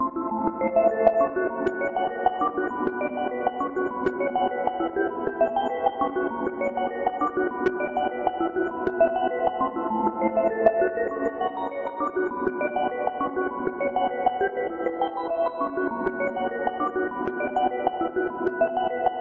描述：我的fm火花效果的第一和最后一部分，有一个多触点延迟。很适合你最喜欢的疯狂科学家的地牢车间。SoundForge8
标签： 效应 循环 火花 合成器
声道立体声